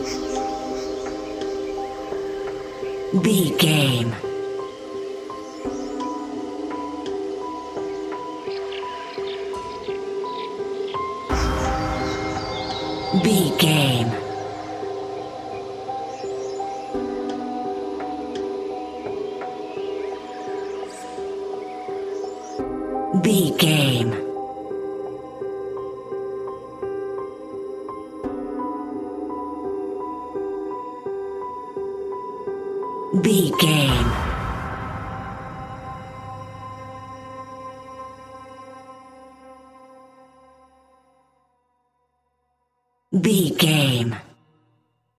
Aeolian/Minor
Slow
ominous
haunting
eerie
piano
synthesiser
creepy
horror music
Horror Pads